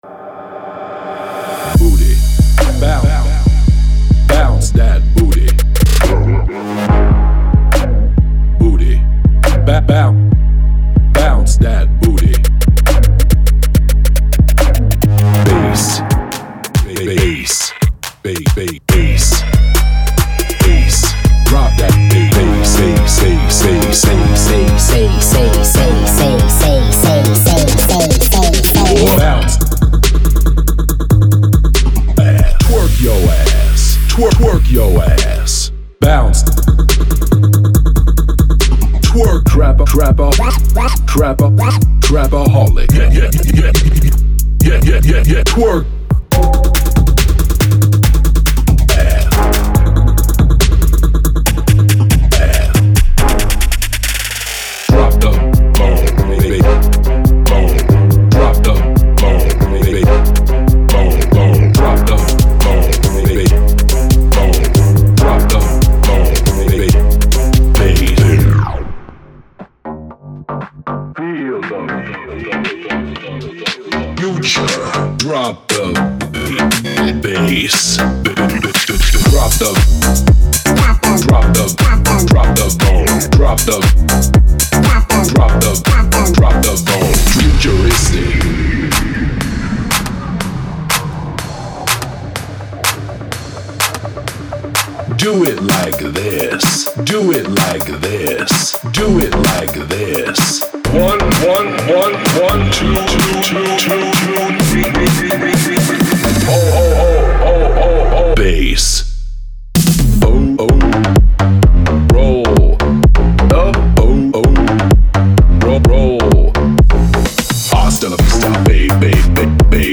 所有样本均由专业配音艺术家录制，整理并处理，以便在您选择应用它们时获得最佳音色。
为了方便起见，我们提供了干声，循环和单次采样的示例，以使您在音乐制作过程中更具创造力。
•该包具有一些明确的内容。
该演示包含其他声音，仅用于说明目的，它们未包含在此示例包中。